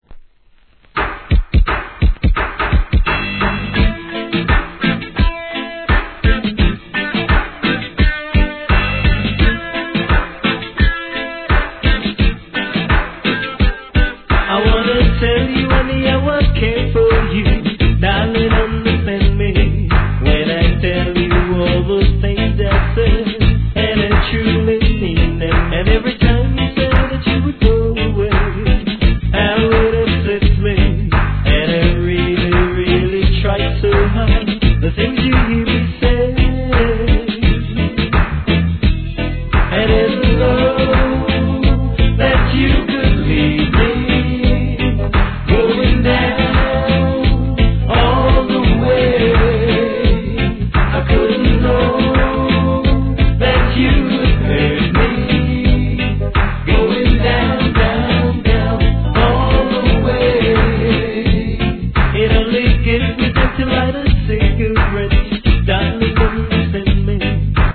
REGGAE
インディー物ながら、イントロのエレキが印象的なRHYTHMにアーバンな香りを漂わす渋ROOTS!!